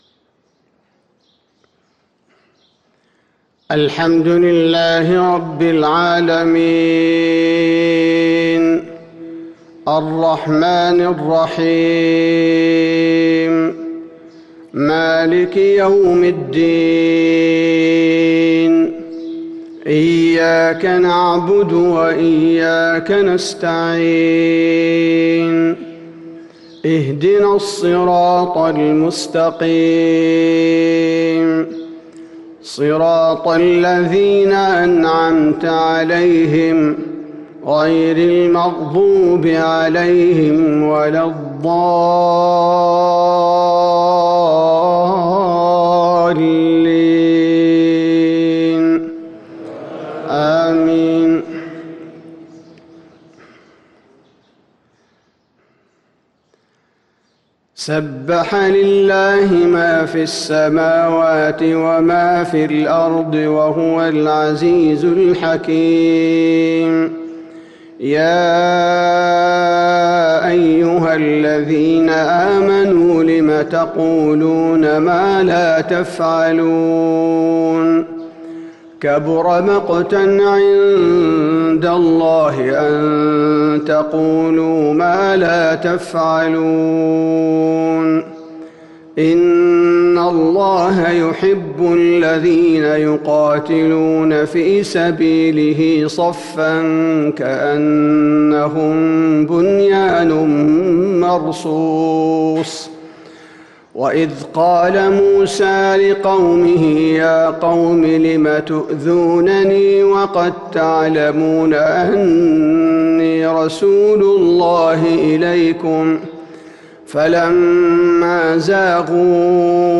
صلاة الفجر للقارئ عبدالباري الثبيتي 1 جمادي الأول 1443 هـ